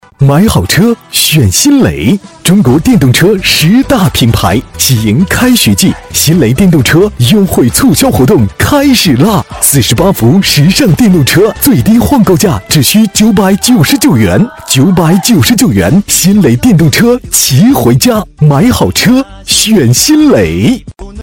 C男150号 | 声腾文化传媒
【促销】新蕾电动车.mp3